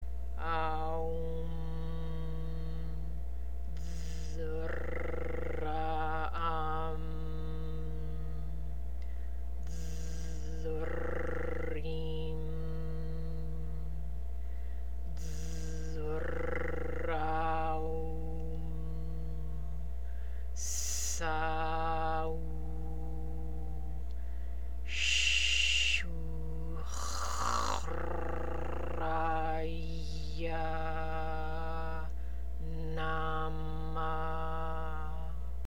МАНТРА ЗА ВЕНЕРА:
AАА-УУУ-MMM ٠ Д-РР-AА- AА-MM ٠ Д-РР-ИИ-MM ٠ Д-РР-AА-УУ-MM ٠ ССА-УУУ ٠ ШШ-УУУ-КХХ-РР-АА-ИЯА ٠ НАА-MAА
Артикулирайте "Р" правилно. "КХ" е силно гърлено.
4 - Venus Square Mantra.mp3